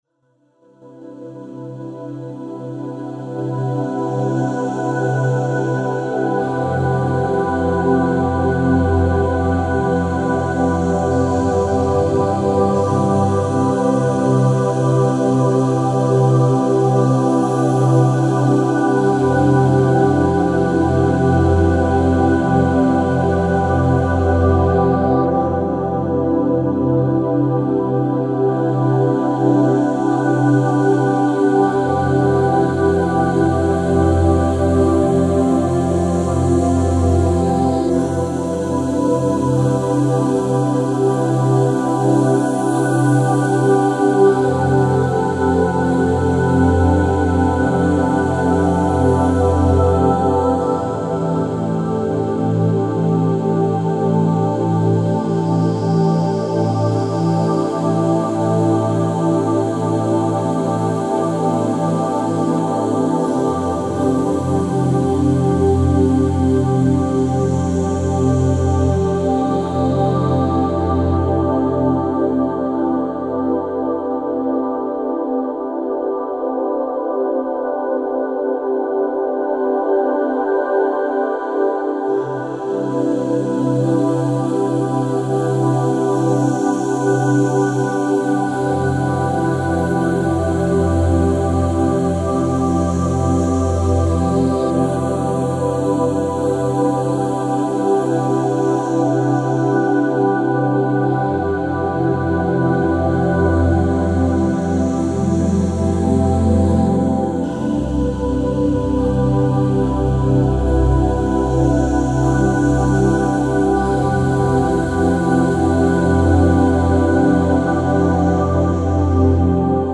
Electronic and Ambient